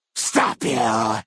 12 KB Category:Fallout: New Vegas creature sounds 1
FNV_GenericFeralGhoulAttack_StopYou.ogg